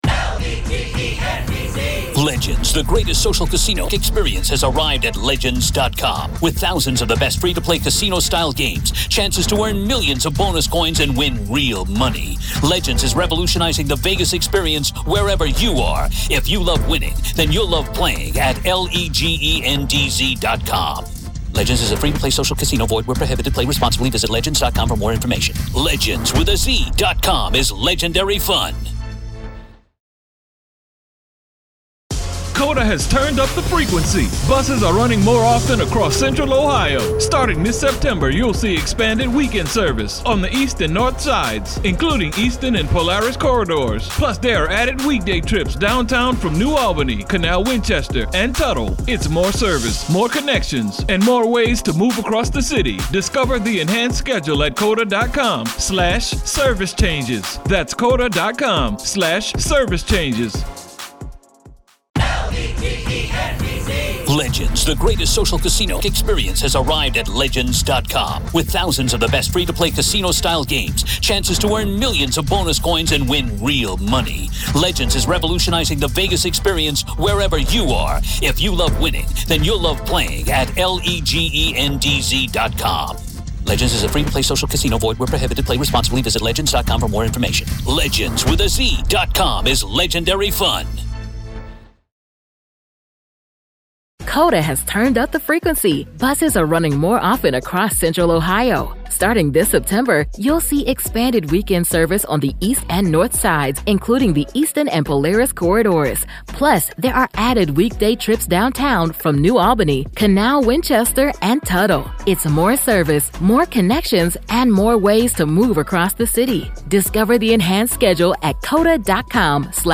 When college student life means more than just sleepless nights, you start to wonder what’s really keeping you awake. A listener shares her eerie experience at a studio apartment near Fort Hays State University.